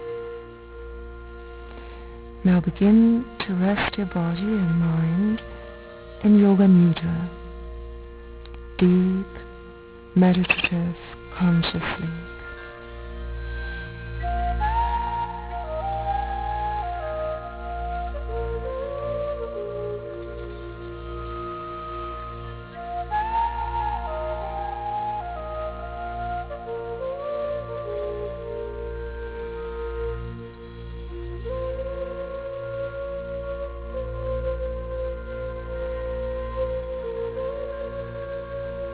Uilleann pipes and low whistle
....this unlikely combination of words and music